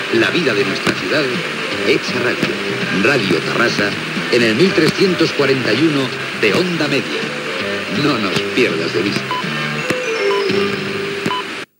Identificació de l'emissora "no nos pierdas de vista"